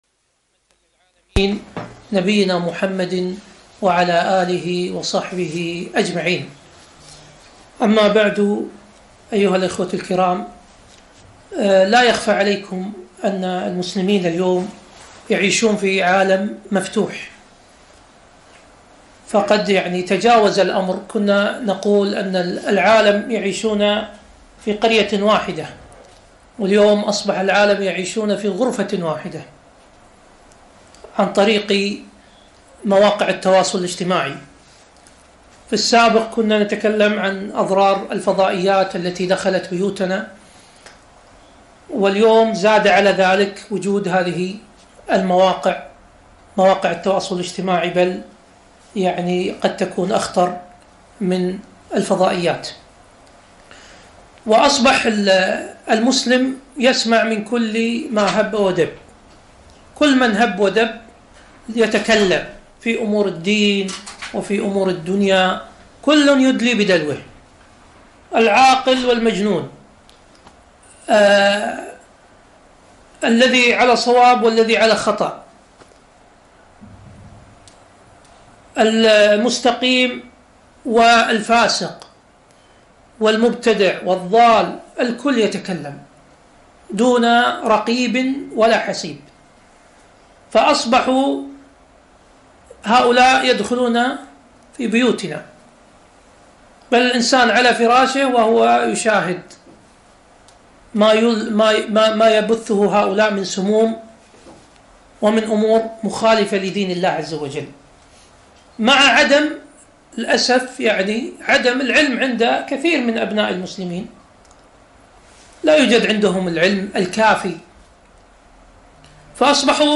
محاضرة - كيفية التعامل مع الشبهات الفكرية